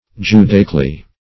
judaically - definition of judaically - synonyms, pronunciation, spelling from Free Dictionary Search Result for " judaically" : The Collaborative International Dictionary of English v.0.48: Judaically \Ju*da"ic*al*ly\, adv.